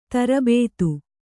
♪ tarabētu